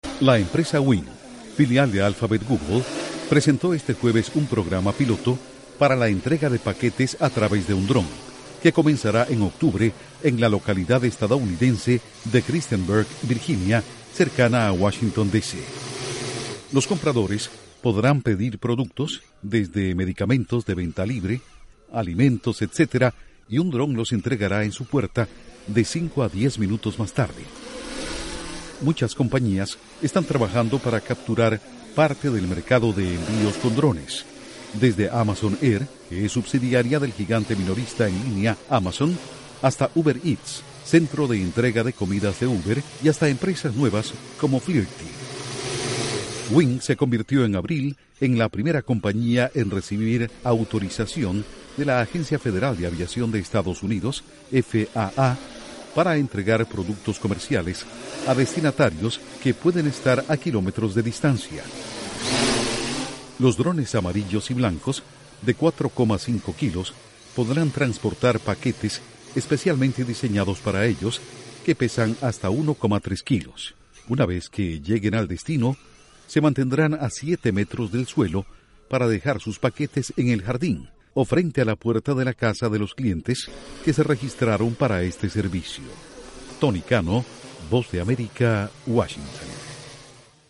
Duración: 1:30 Con audios de drones